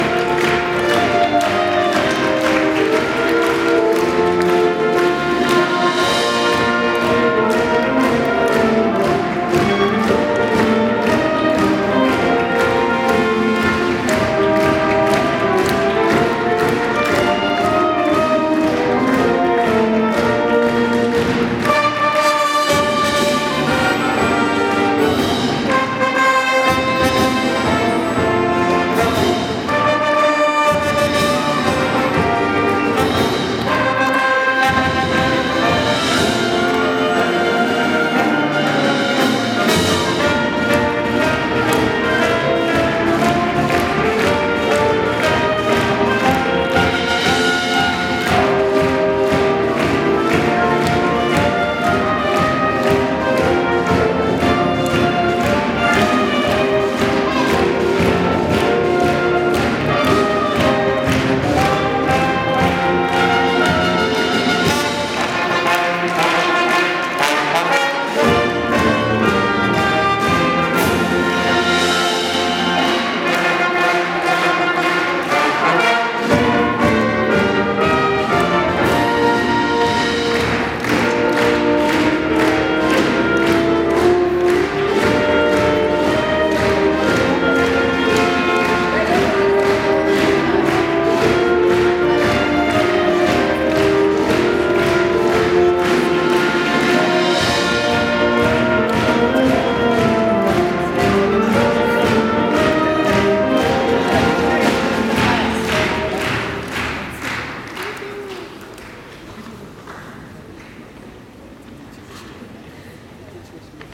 収め式の後は 関東大会 の壮行会が執り行われました。 今回も、生徒会の生徒たちの取り仕切りの元、 吹奏楽部の演奏とともにさらなる エール を送りました！